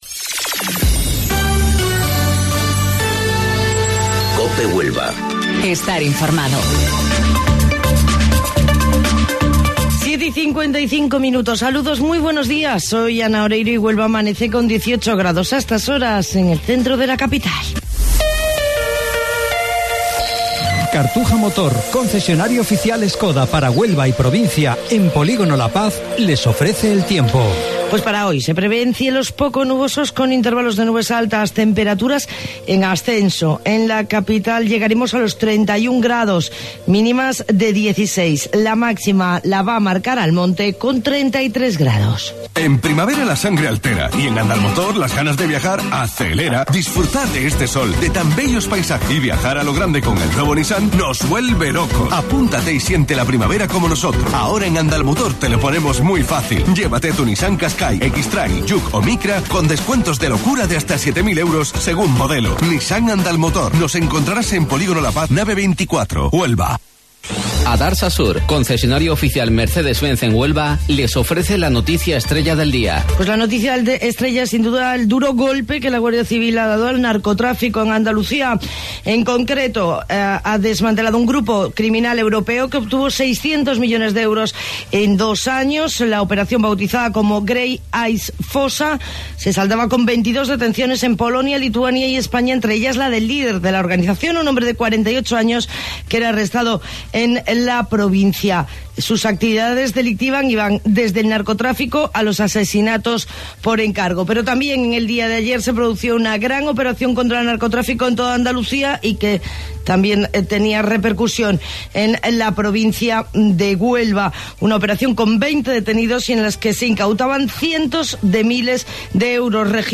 AUDIO: Informativo Local 07:55 del 23 de Mayo